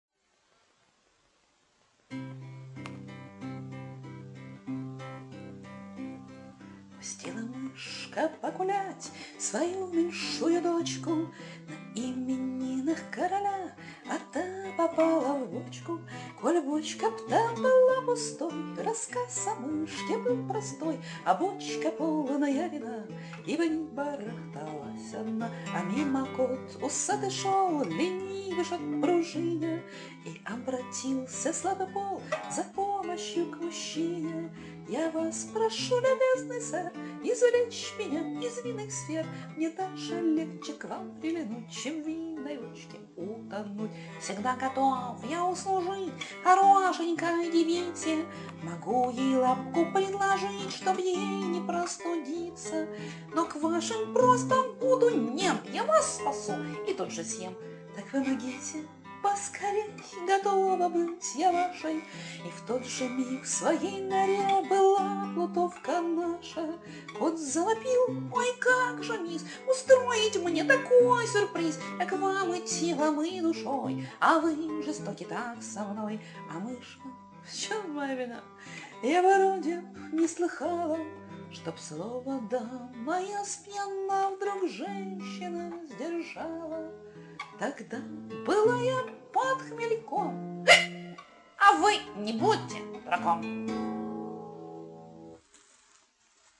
Были у меня в 80-х записи бардов - вдвоем пели.